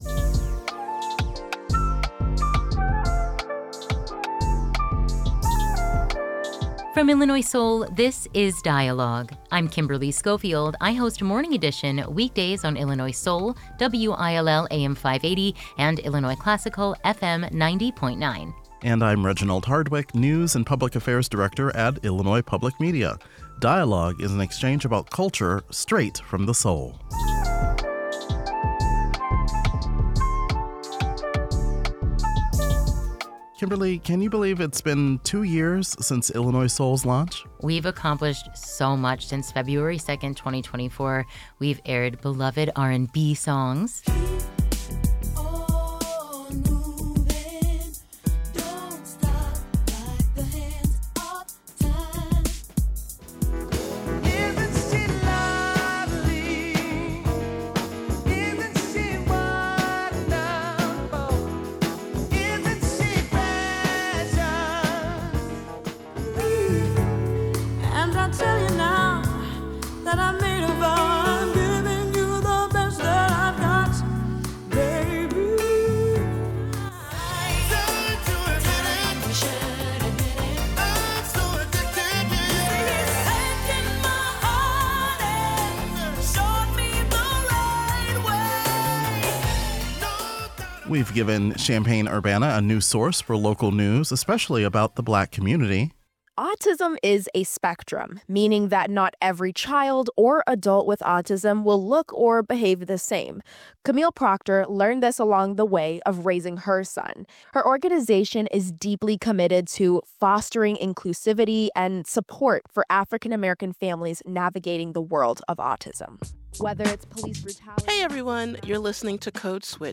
Grammy-nominee Maysa talks about her 3-decade career as she prepares to perform for Illinois Soul's second year celebration on February 7, 2026.